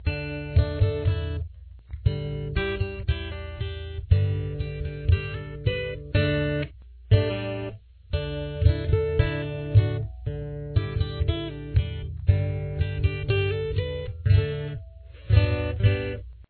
• Key Of: D
• Instruments: Acoustic Guitar